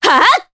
Artemia-Vox_Attack1_jp.wav